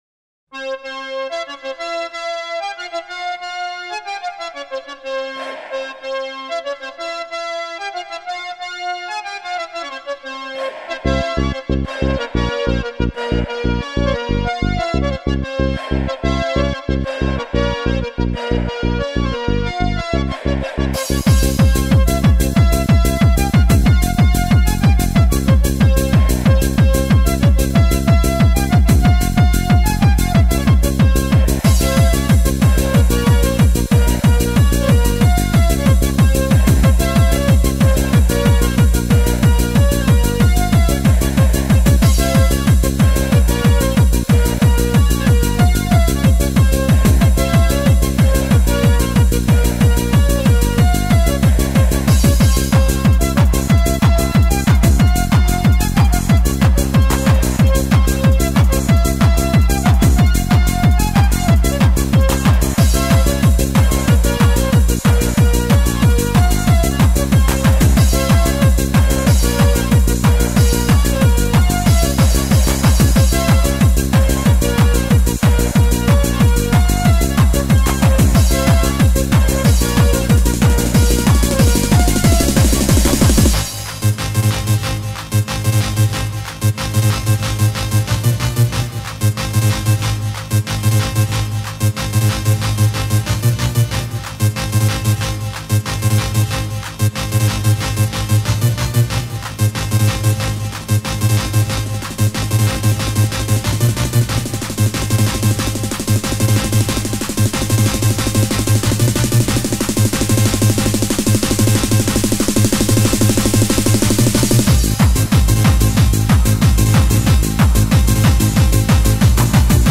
hava_hava_nagila_club_mix.mp3